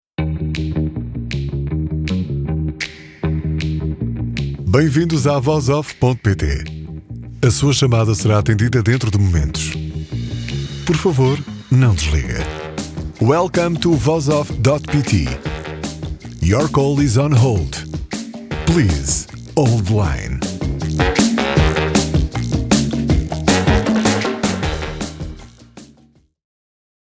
Produção e gravação de Esperas Telefónicas / Atendimento telefónico automático
Produção-e-gravação-de-Esperas-Telefónicas-_-Atendimento-telefónico-automático_v1.mp3